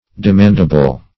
Search Result for " demandable" : The Collaborative International Dictionary of English v.0.48: Demandable \De*mand"a*ble\, a. That may be demanded or claimed.